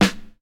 Sharp Snare Sample F Key 102.wav
Royality free snare single hit tuned to the F note. Loudest frequency: 1453Hz
sharp-snare-sample-f-key-102-kdD.mp3